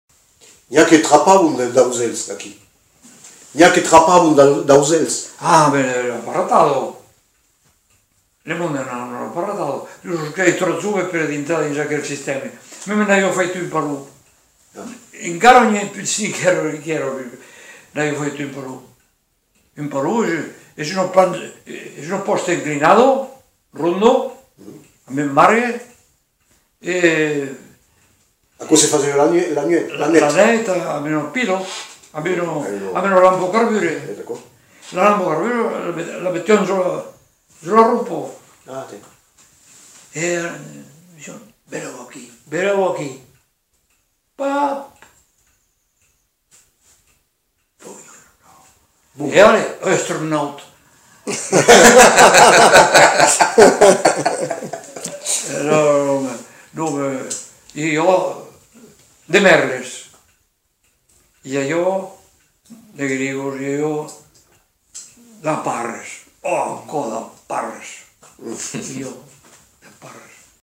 Aire culturelle : Lauragais
Lieu : Le Faget
Genre : témoignage thématique